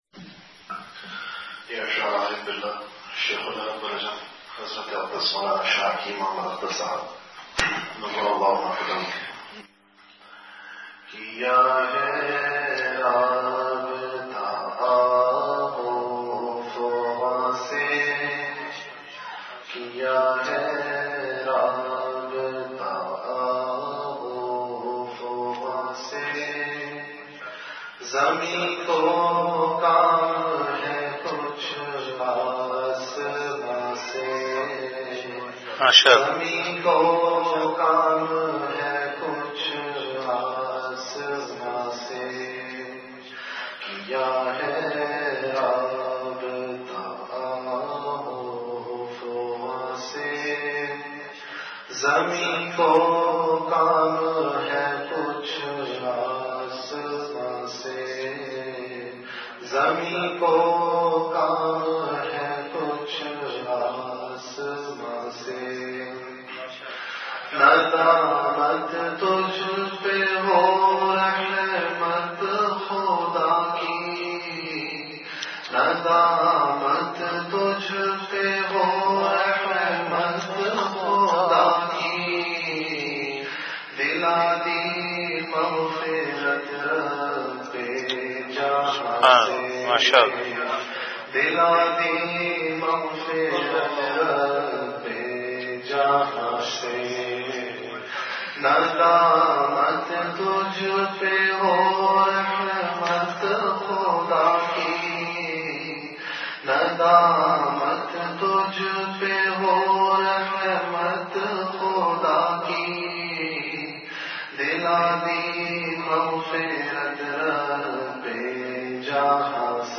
Delivered at Home.
Majlis-e-Zikr